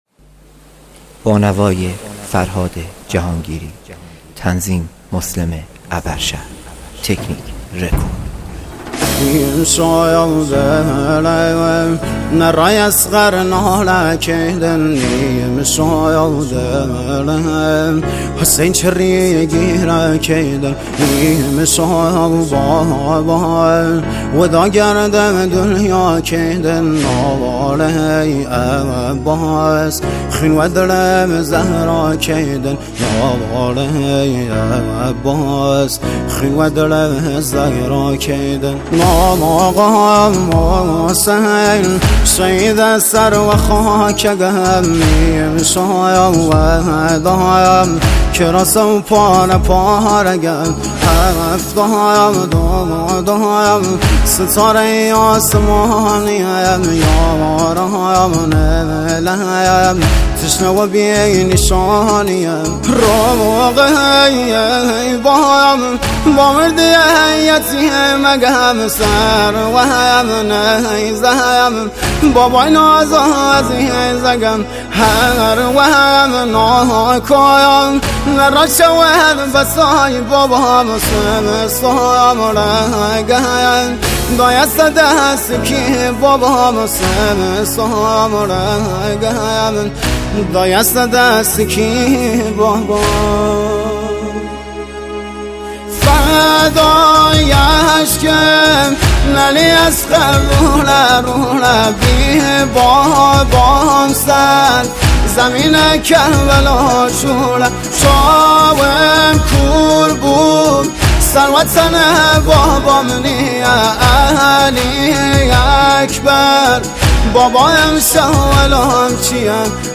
دانلود مجموعه مداحی و نوحه ماه محرم